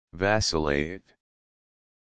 Audio Pronunciation of Vacillate